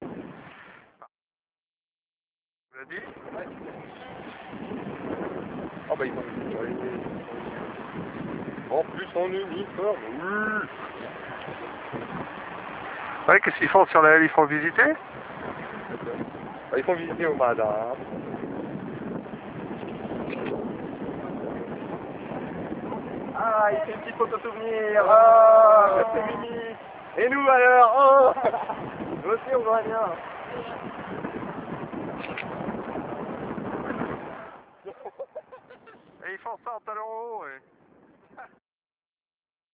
Mini vidéo à la DM